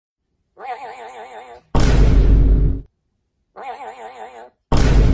Red-bird-gumi-meme Dramatic-boom